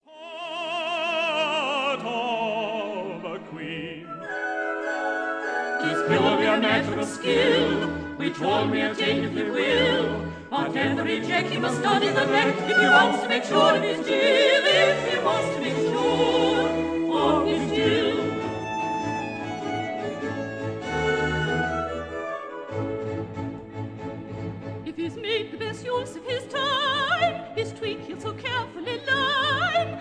tenor
soprano
contralto